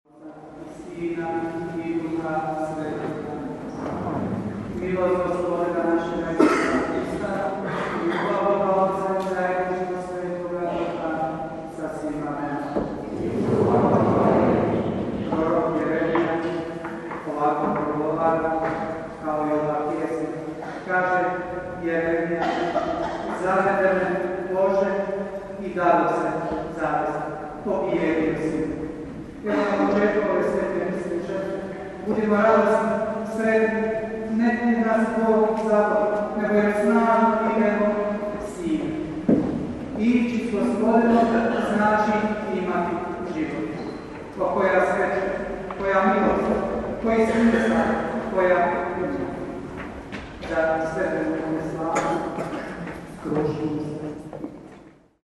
UVODNA MISAO